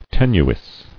[ten·u·is]